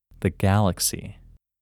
WHOLENESS English Male 13
WHOLENESS-English-Male-13.mp3